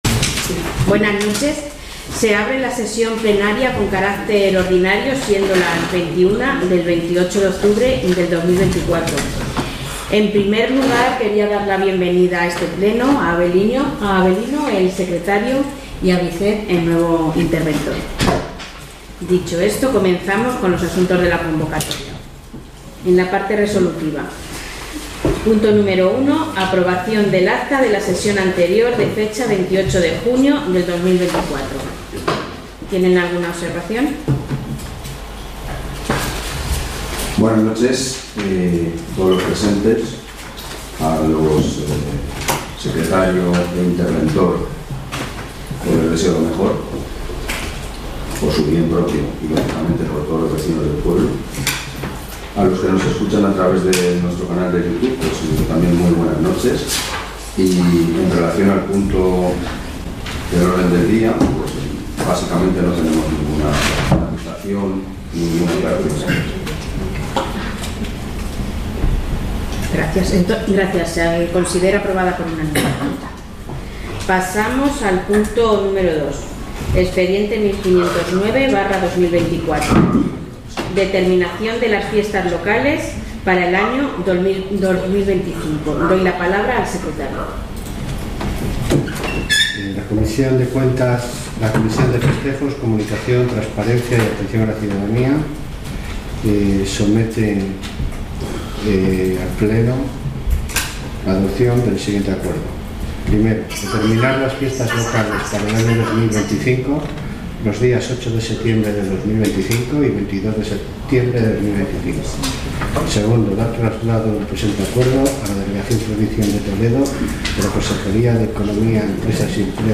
Pleno Ordinario (28/10/2024).